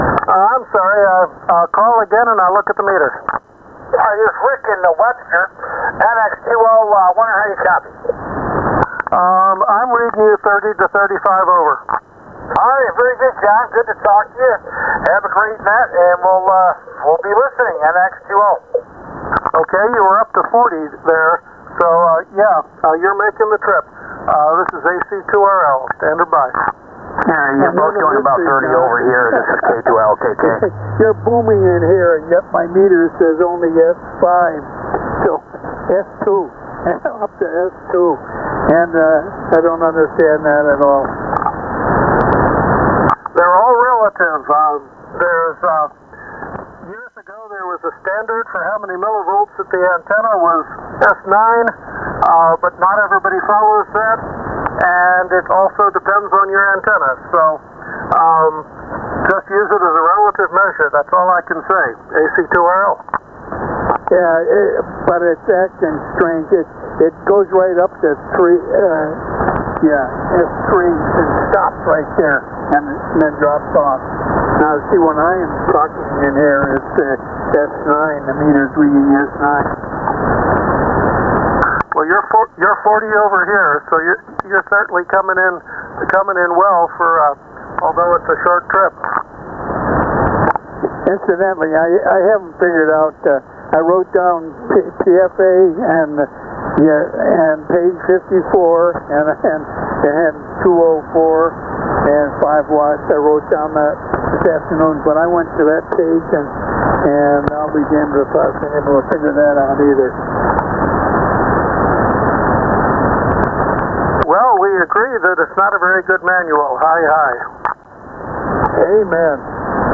Audio recordings from the Roc City Net 75 Meter Net on 3.810 Mhz